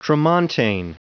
Prononciation du mot tramontane en anglais (fichier audio)
Prononciation du mot : tramontane